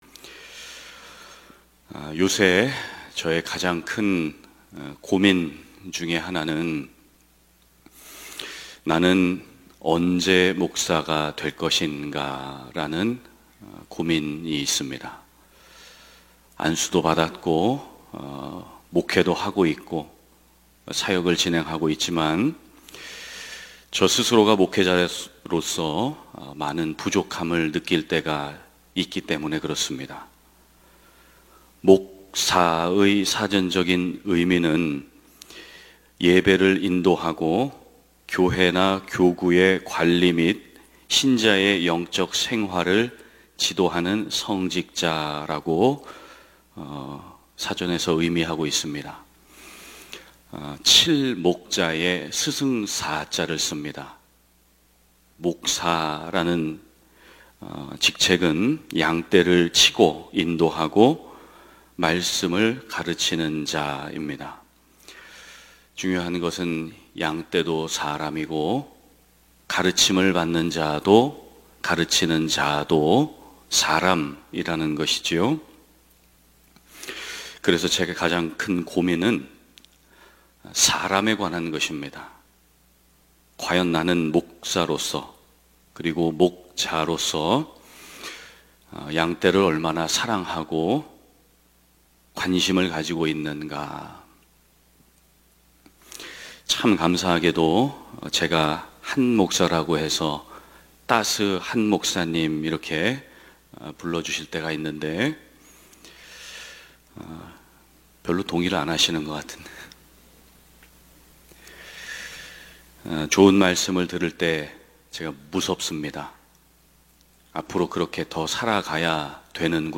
예배: 평일 새벽